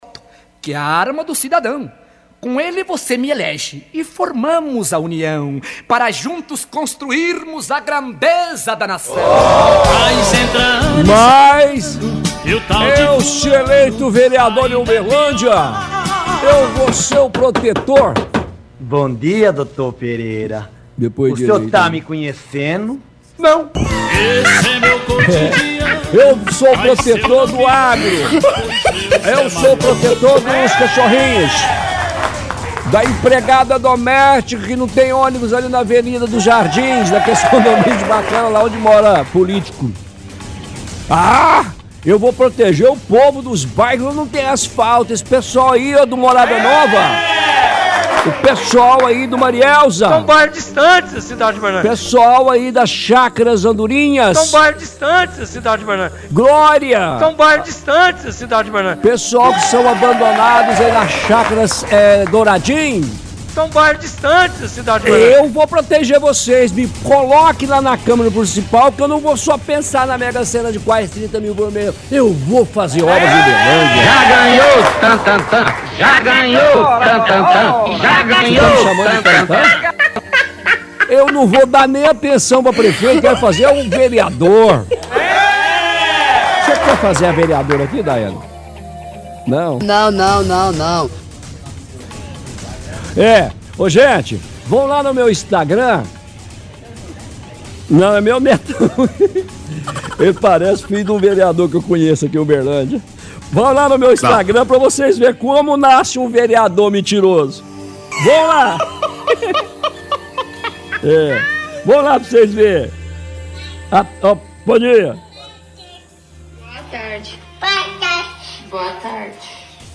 Coloca áudio antigo do prefeito.